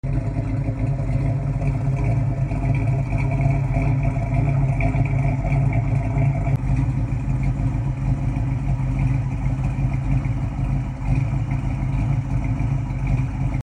Amazing 428 V8 Cobra Jet sound effects free download
Amazing 428 V8 Cobra Jet Exhaust Sound